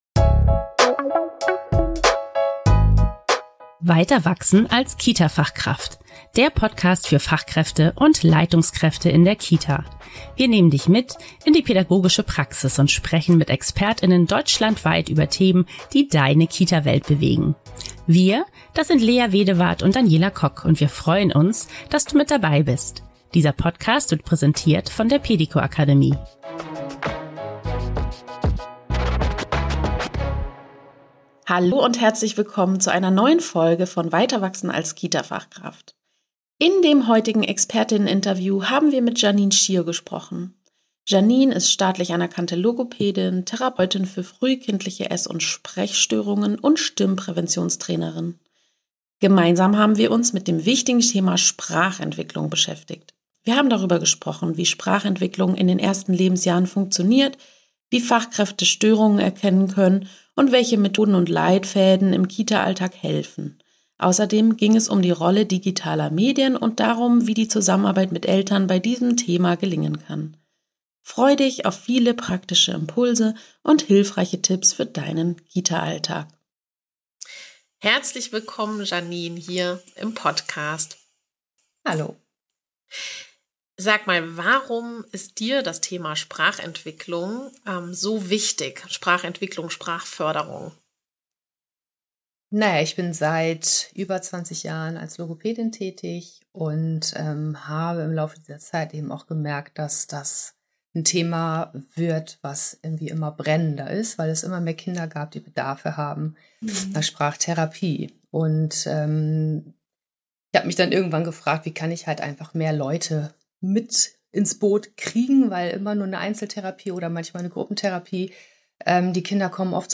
Unser heutiger Gast im Experteninterview